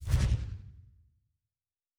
Special Click 31.wav